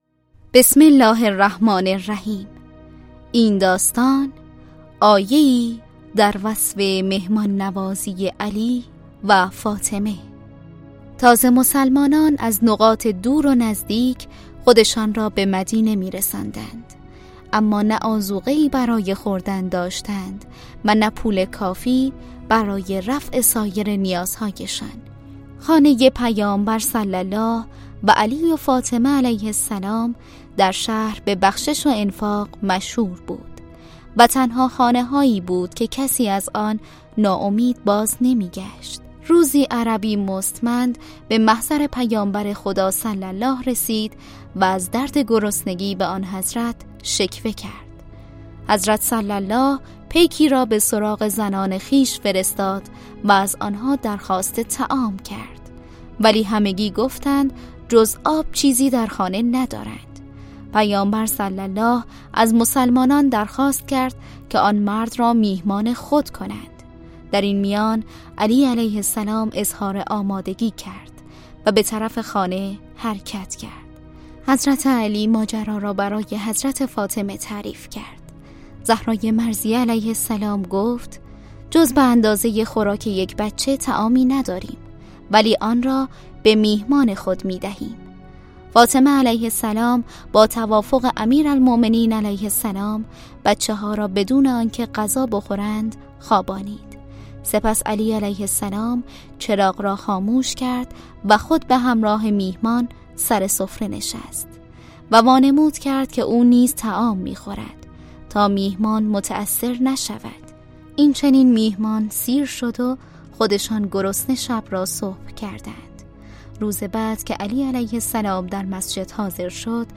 کتاب صوتی مهربانو